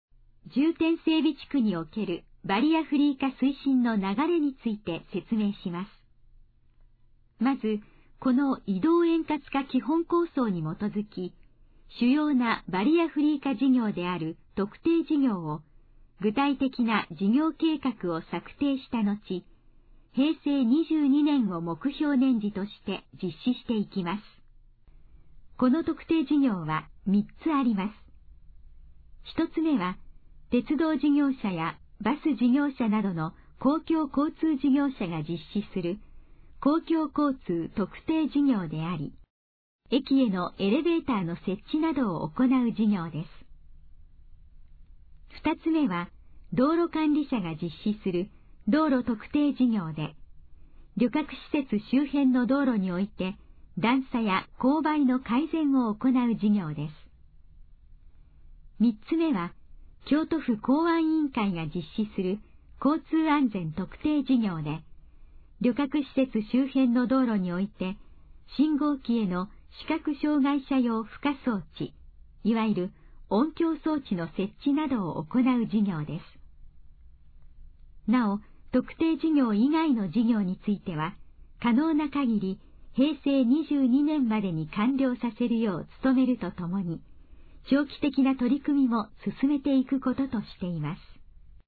このページの要約を音声で読み上げます。
ナレーション再生 約180KB